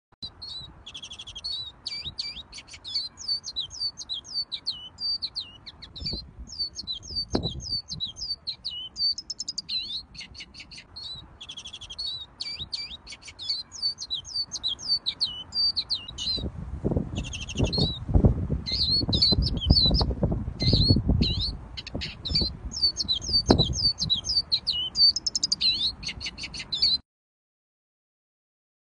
黄雀悦耳叫声